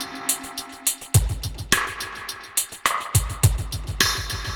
Index of /musicradar/dub-drums-samples/105bpm
Db_DrumKitC_EchoKit_105-01.wav